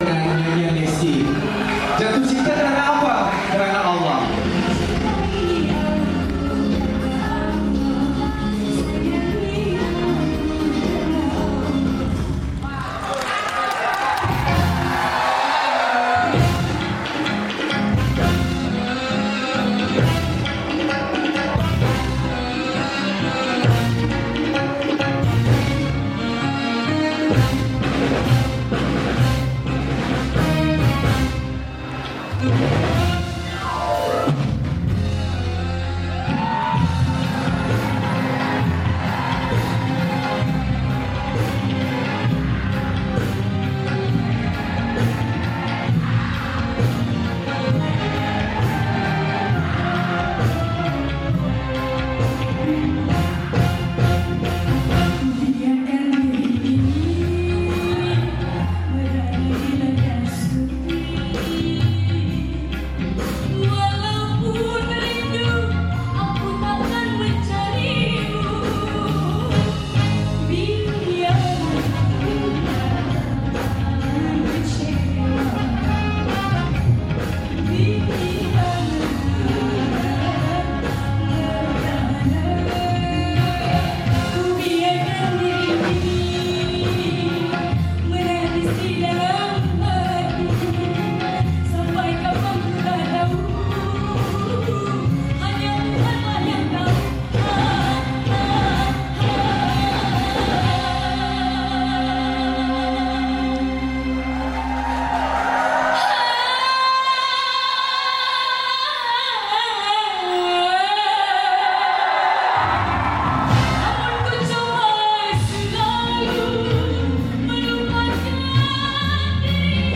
penyanyi dangdut yg memiliki bunyi khas itu